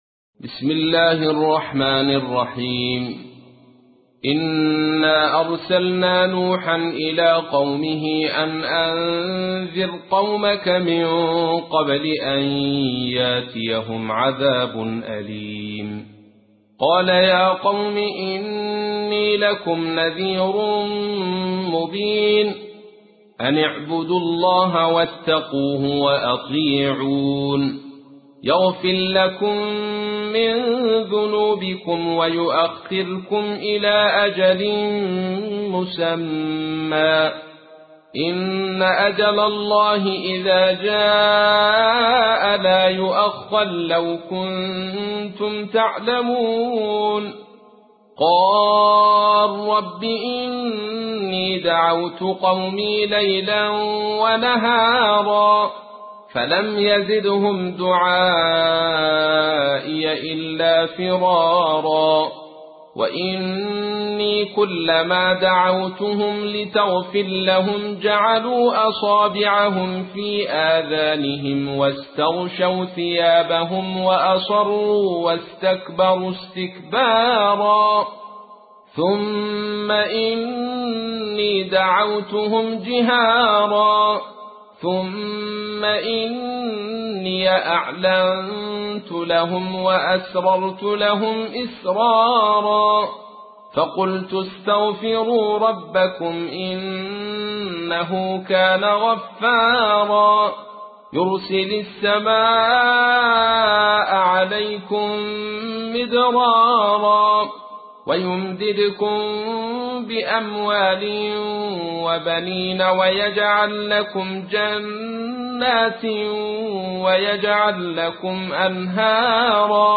تحميل : 71. سورة نوح / القارئ عبد الرشيد صوفي / القرآن الكريم / موقع يا حسين